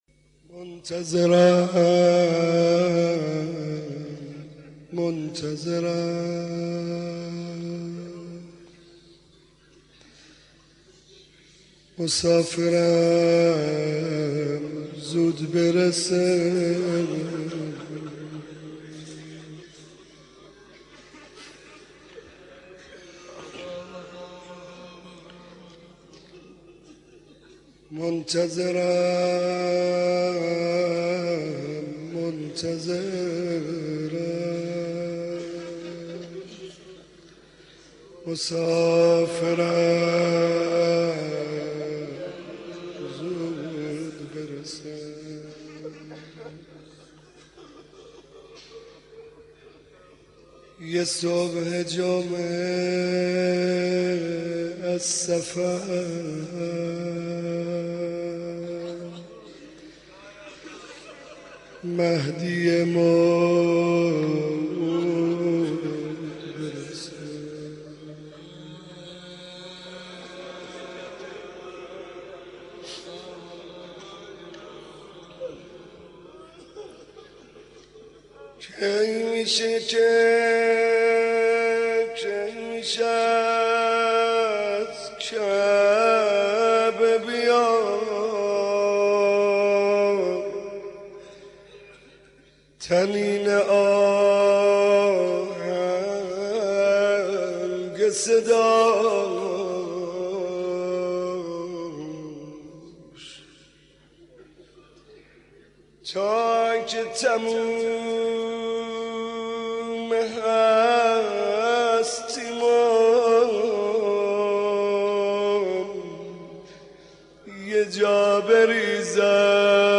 مداح
مناسبت : عاشورای حسینی
مداح : محمدرضا طاهری